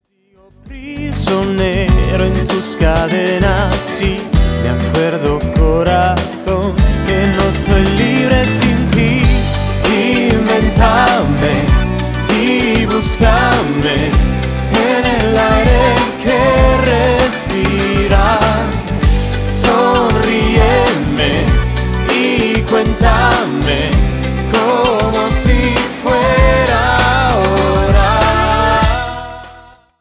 POP (03.17) Base Testo Strum.